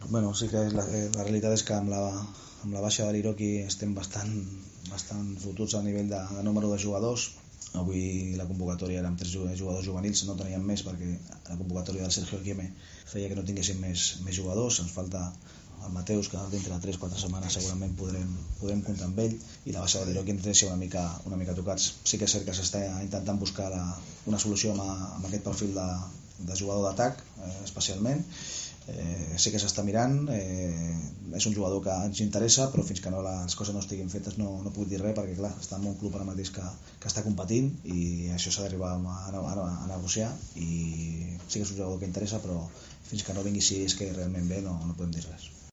rueda de prensa posterior al Barça B 0 - Hércules 0